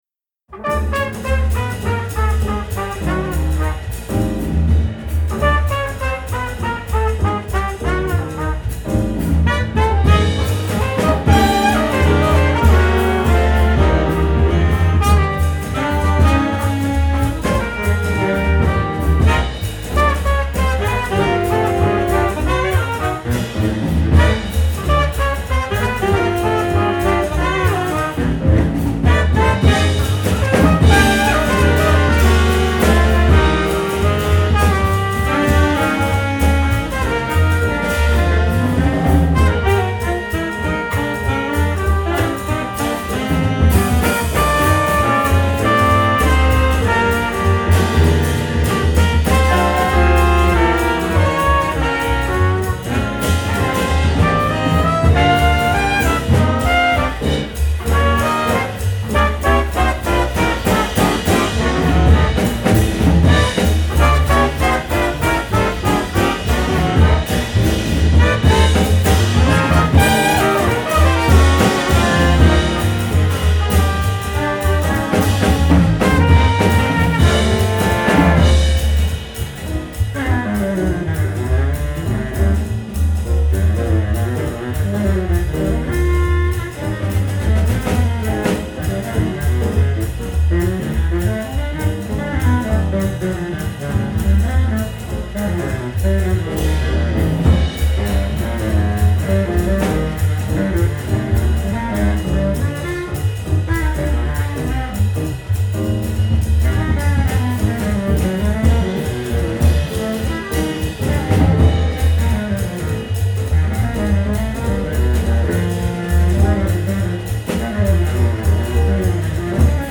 Mainstream Bop-Oriented Jazz